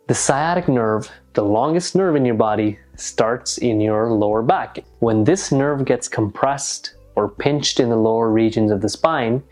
Oigamos ahora a un hablante de inglés americano pronunciar la palabra lower:
lower-smoothing.mp3